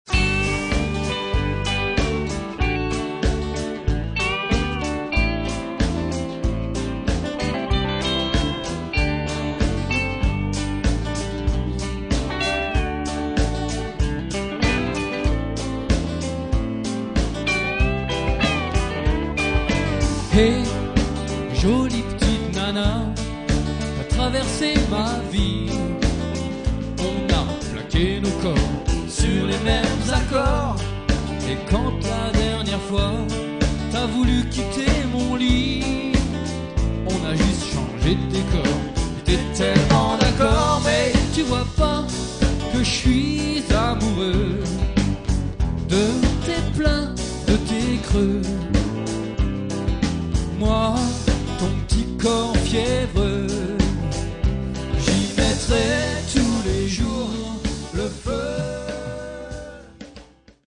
Vieux extraits avec un groupe de Blues/rock (rien à voir avec ce que je fais aujourd'hui, ultra simpliste, mais marrant ) :
Un excellent groove)